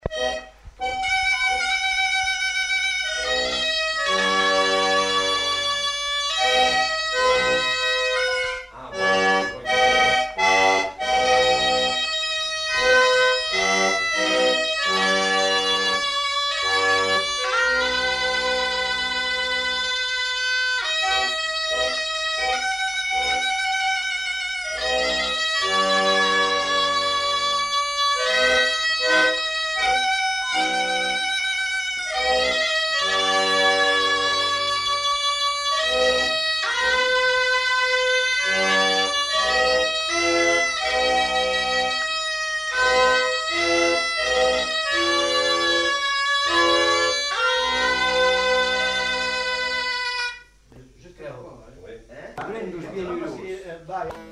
Lieu : Pyrénées-Atlantiques
Genre : morceau instrumental
Instrument de musique : accordéon chromatique ; cabrette